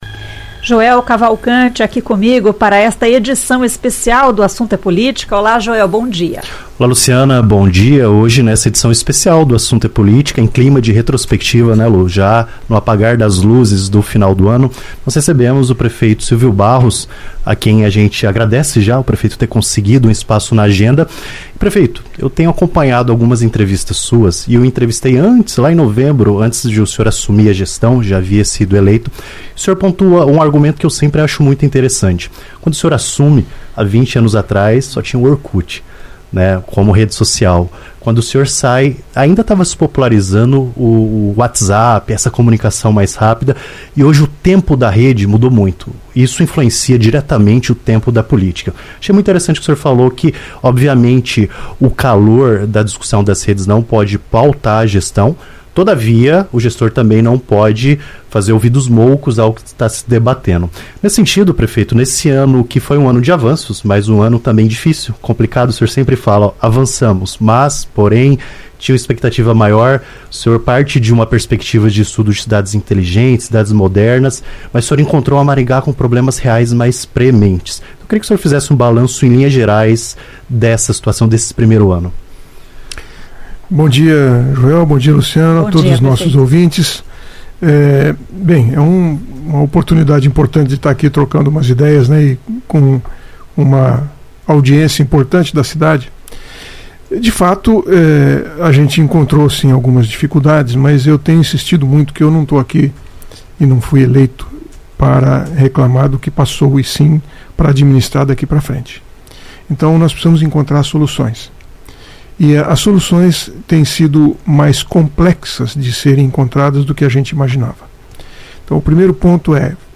O prefeito de Maringá, Silvio Barros, foi o convidado da coluna O Assunto é Política nesta sexta-feira (19).